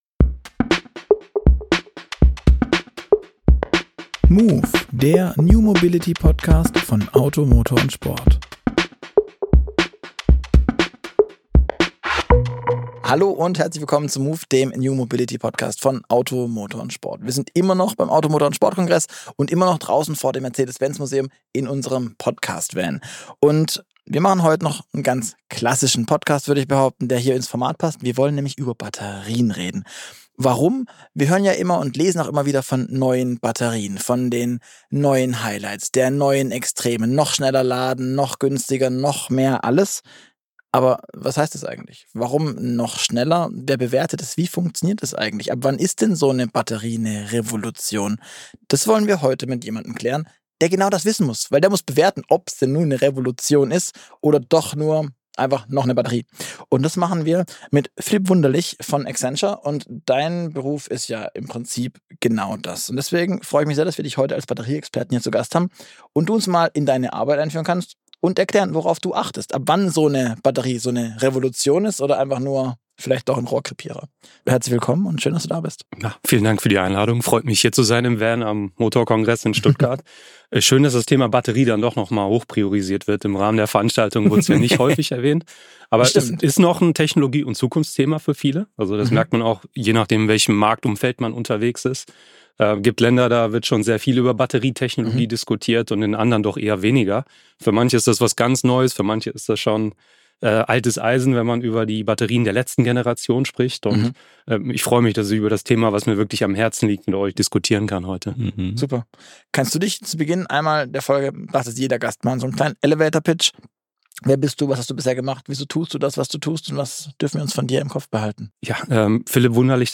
Ein Gespräch über Zellchemien, Produktionsprobleme, geopolitische Abhängigkeiten – und den vielleicht wichtig